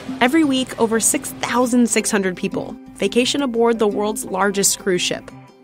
In this recording you can see the assimilation case where there is a change of both place of articulation and voicing (/z/ becoming /ʃ/):